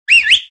Whistle2.wav